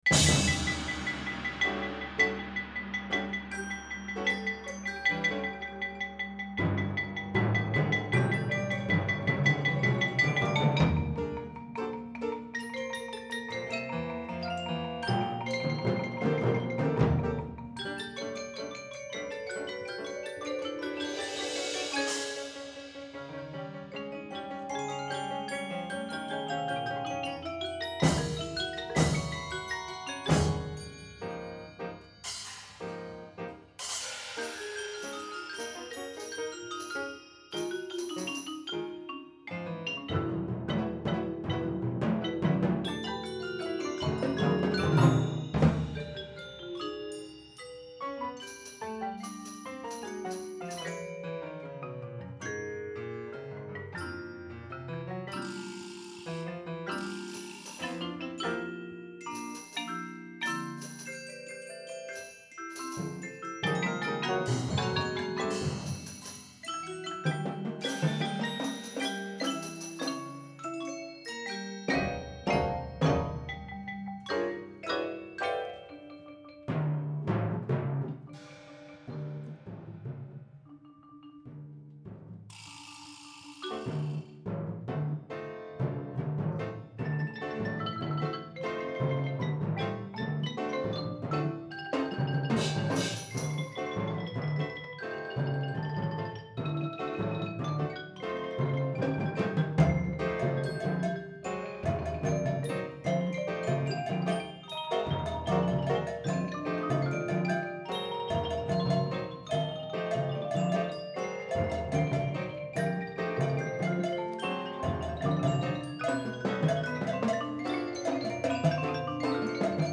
Genre: Percussion Ensemble
# of Players: 13 + Piano
a high-energy joy ride for large percussion ensemble
Crotales
Xylophon
Vibraphone (3-octave)
Marimba 1 (4-octave)
Piano
Timpani (5)
Cymbals (Ride, Splash, Chinese, Crash, Suspended)